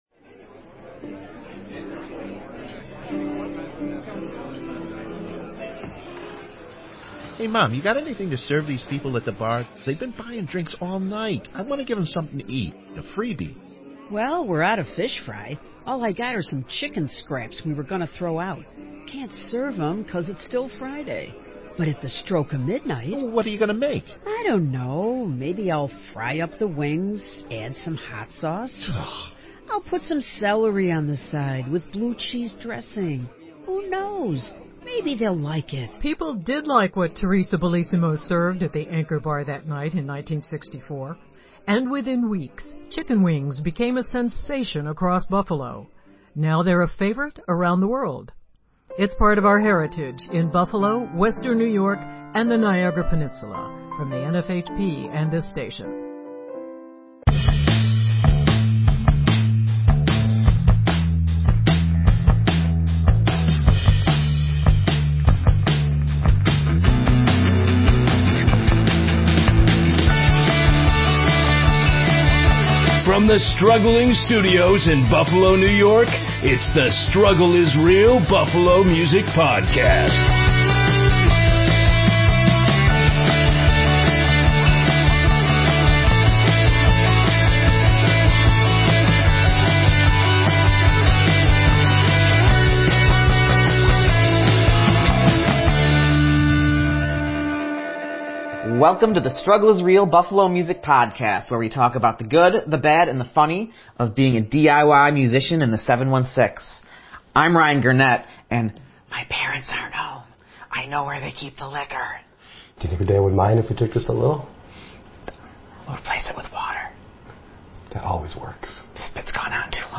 INTERVIEW (cont’d)